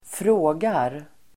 Uttal: [²fr'å:gar]